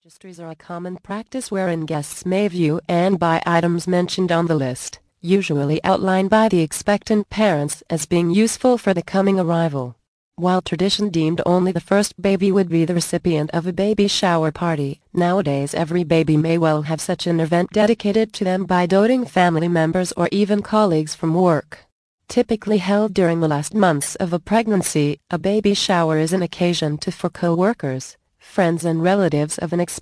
The Ultimate Baby Shower Guide mp3 audio book Vol. 2